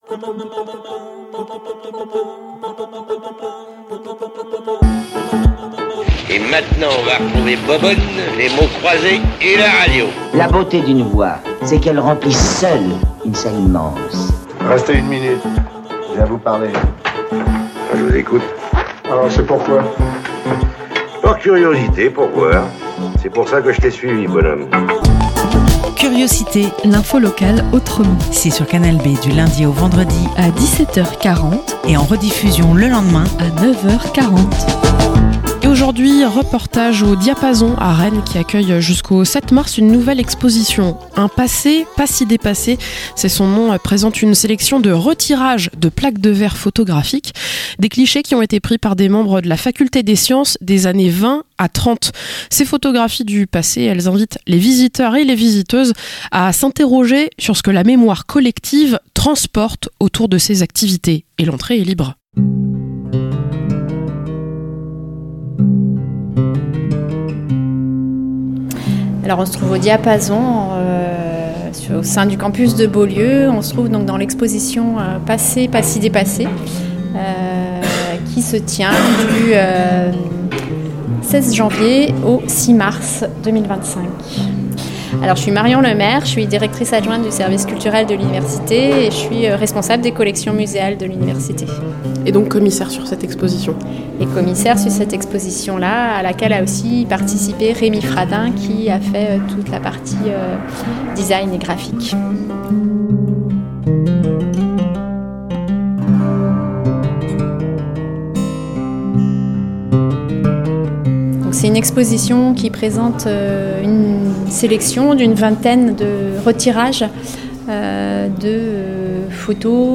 - Reportage au Diapason sur le campus Beaulieu pour découvrir l'exposition "Un passé si pas dépassé". Une sélection de retirages de plaques de verre photographiques issues des collections de l'Université.